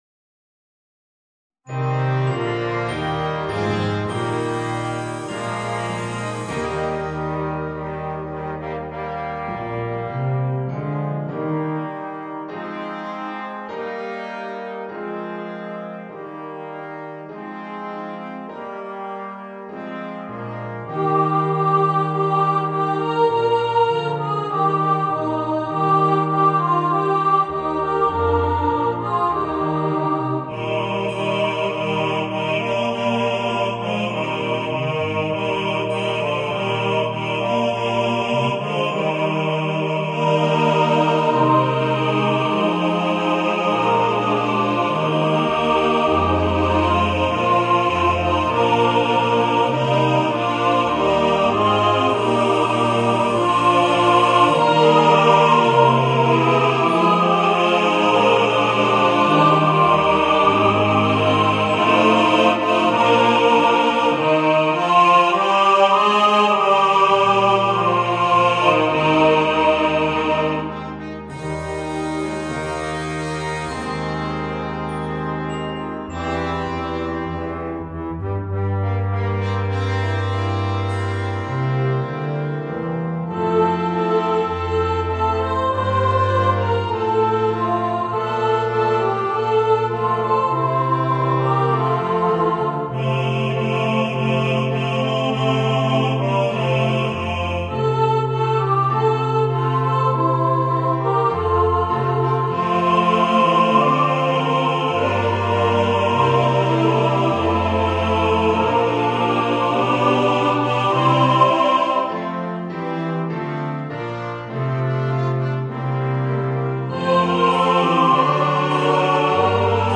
Voicing: Chorus